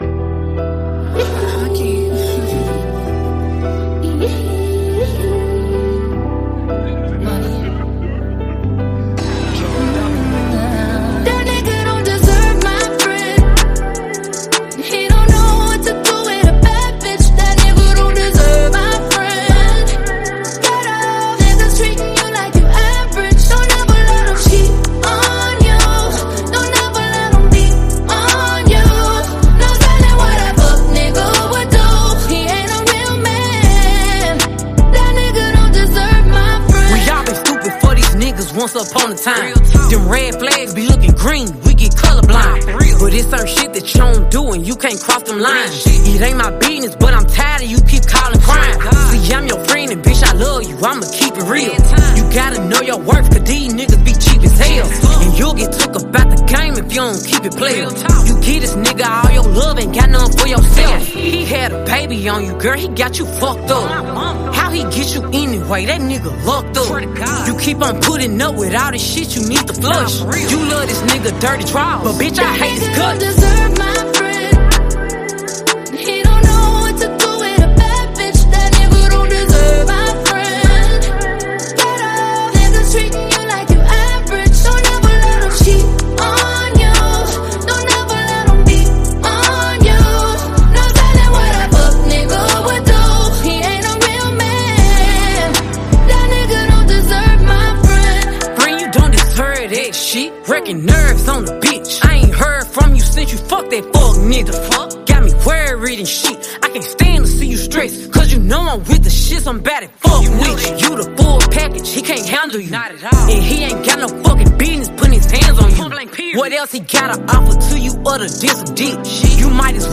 Trap/Soul